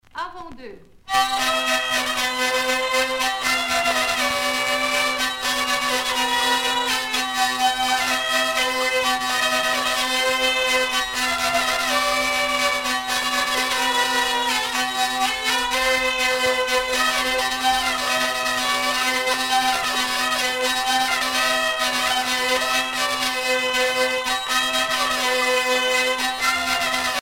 Région ou province Morvan
danse : quadrille : avant-deux
Pièce musicale éditée